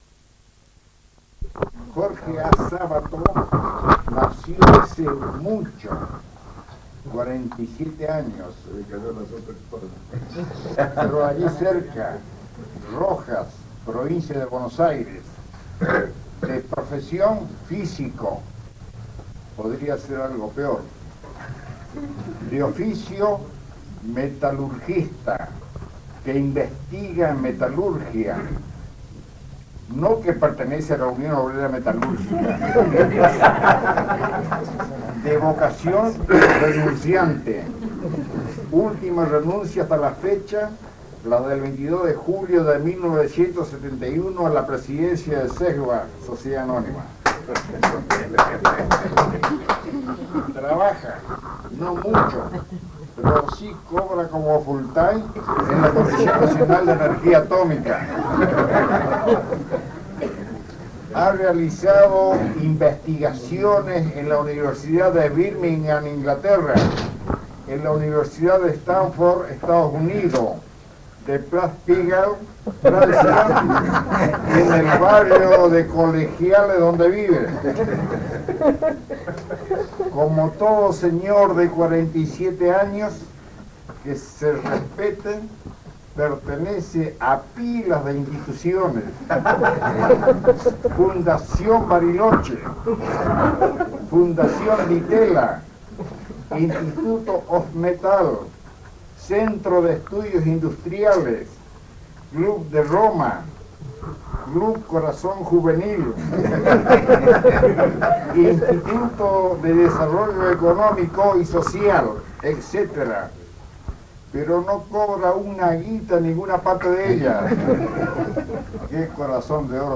Charla coloquial realizada en el "Fogón de los arrieros" acerca del tango, el fútbol y la crisis.